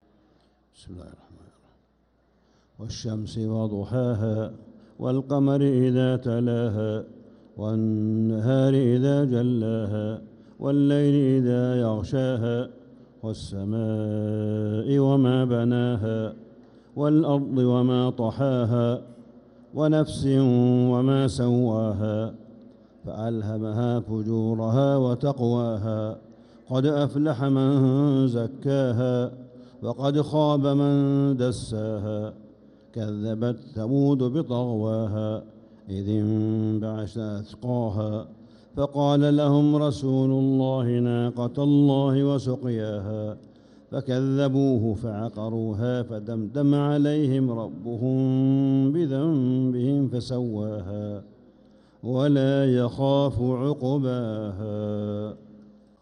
سورة الشمس 1-9-1446هـ | Surah Ash-Shams > السور المكتملة للشيخ صالح بن حميد من الحرم المكي 🕋 > السور المكتملة 🕋 > المزيد - تلاوات الحرمين